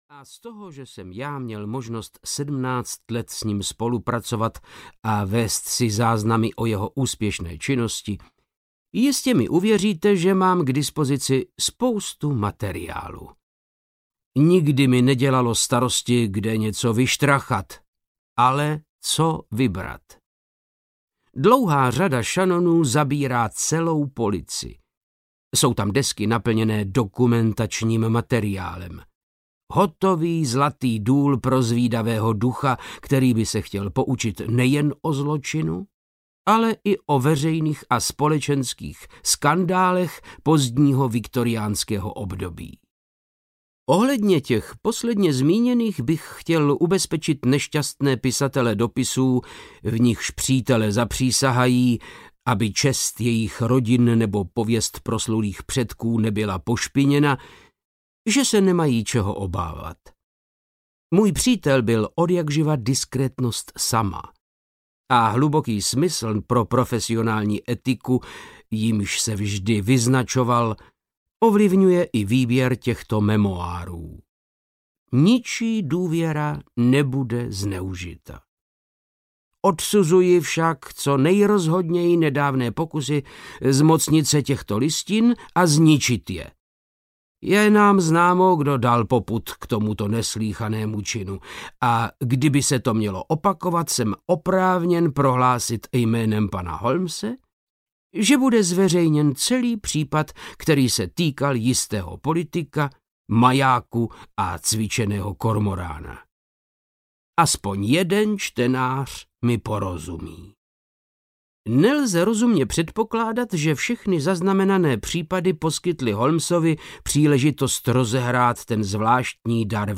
Podnájemnice v závoji audiokniha
Ukázka z knihy
• InterpretVáclav Knop